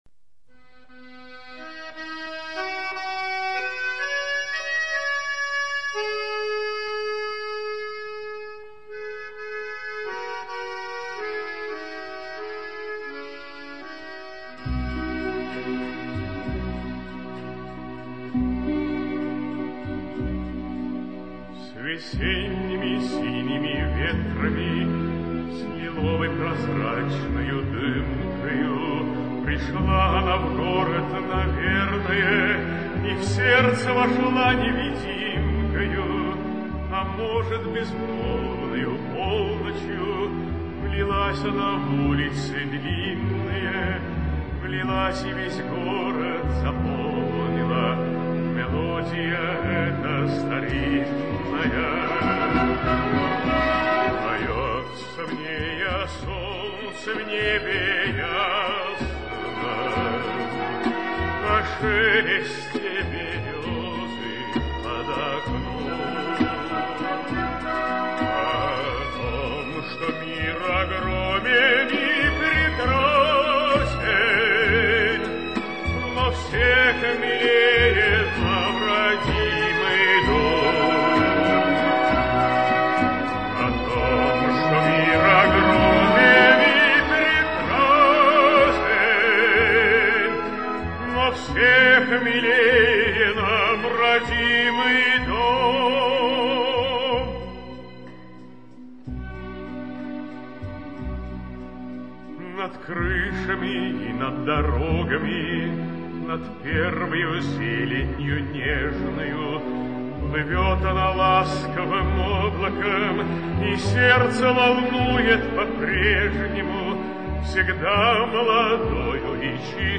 Красивая мелодия с сочетением неповторимого тембра голоса